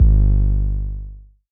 JJCustom808s (6).wav